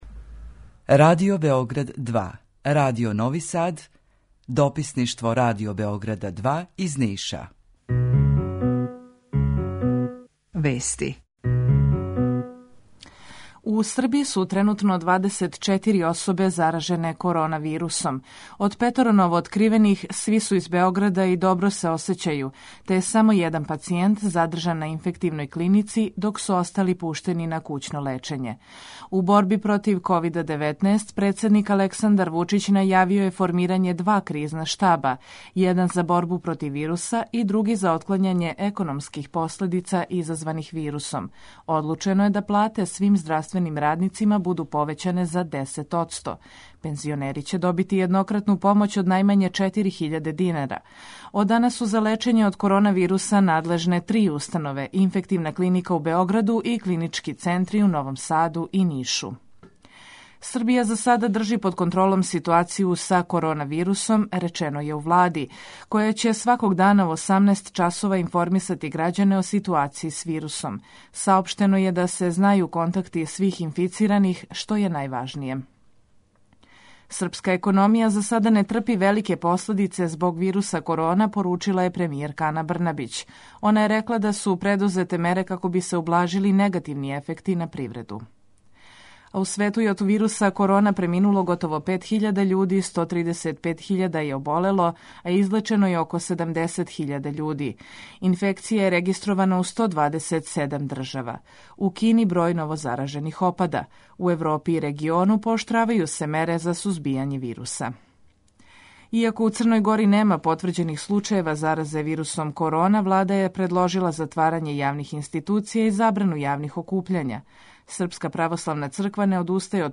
Jутарњи програм заједнички реализују Радио Београд 2, Радио Нови Сад и дописништво Радио Београда из Ниша. Cлушаоци могу да чују најновије информације из сва три града, а петком и информације из Бања Луке од колега из Радио Републике Српске.
У два сата, ту је и добра музика, другачија у односу на остале радио-станице.